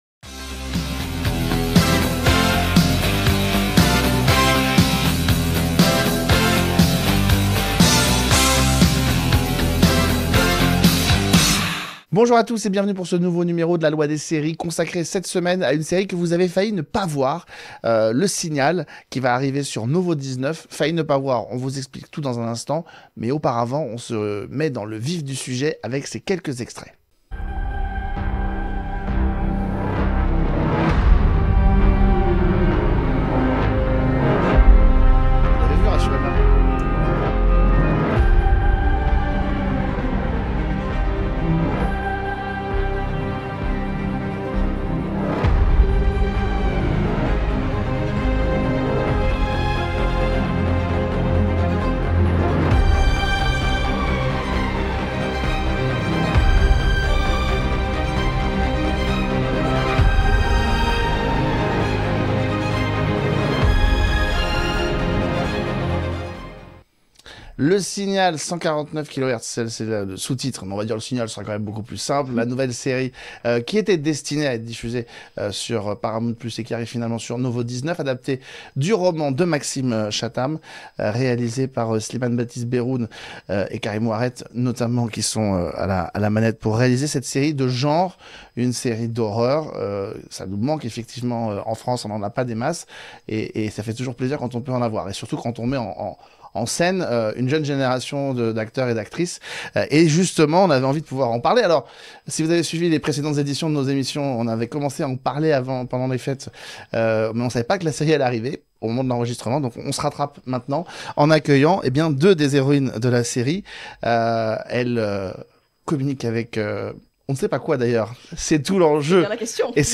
Les invitées